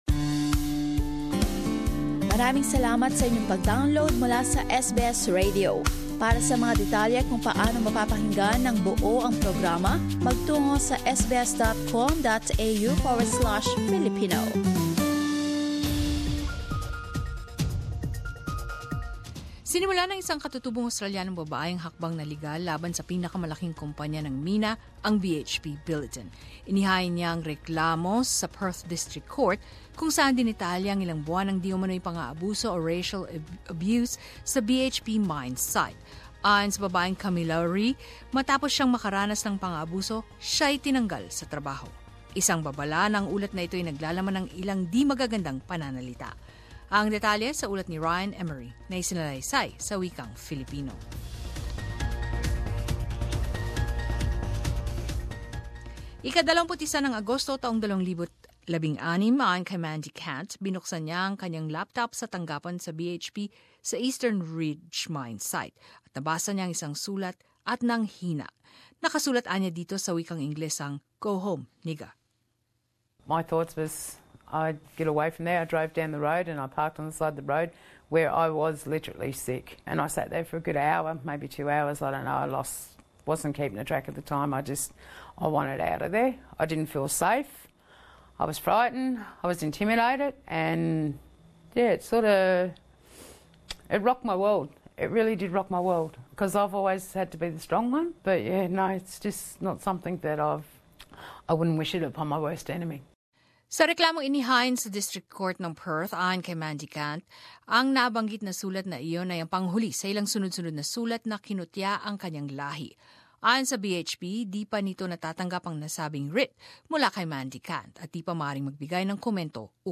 A warning that this report contains some offensive language.